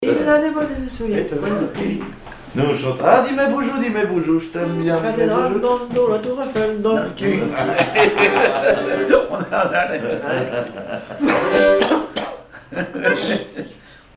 Genre brève
Répertoire de bal au violon et accordéon
Pièce musicale inédite